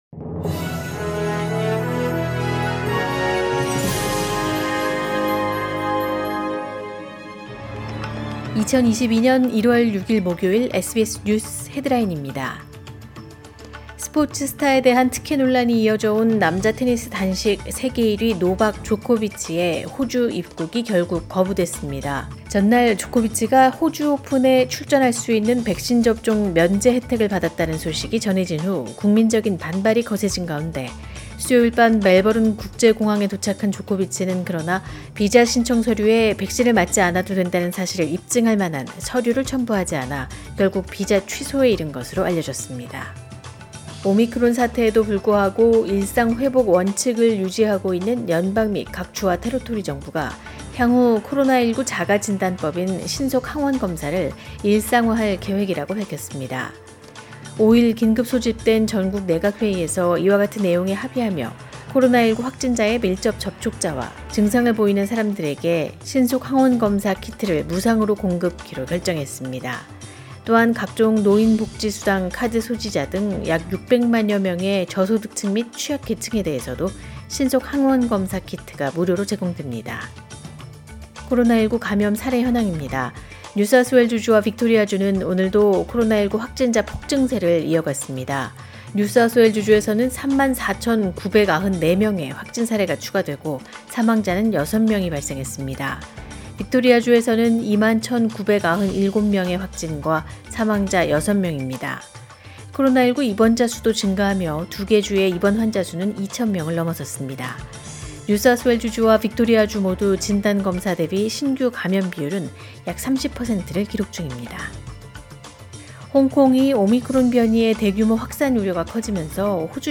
2022년 1월 6일 목요일 오전의 SBS 뉴스 헤드라인입니다.